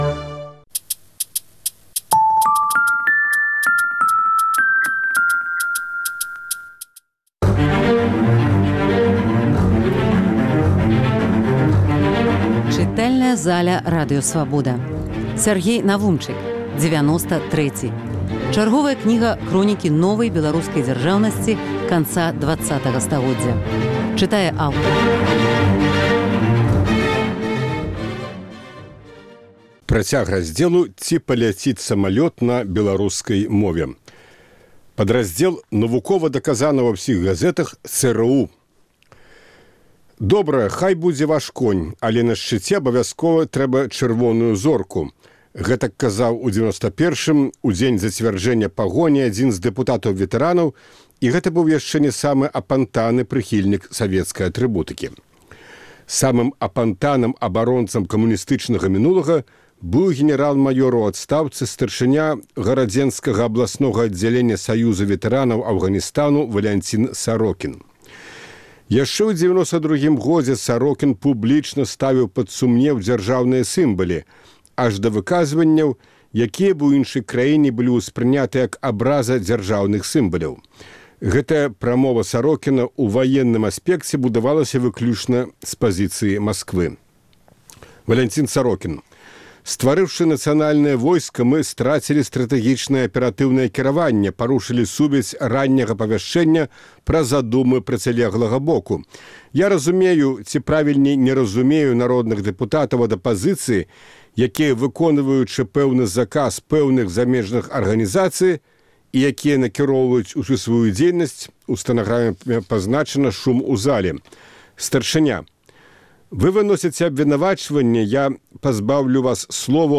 Фрагмэнты кнігі Сяргея Навумчыка "Дзевяноста трэці". Чытае аўтар.